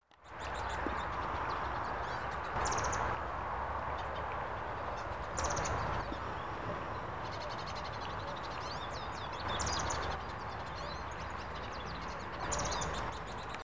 White-crested Tyrannulet (Serpophaga subcristata)
Se escuchan cabecitas negras también.
Country: Argentina
Condition: Wild
Certainty: Photographed, Recorded vocal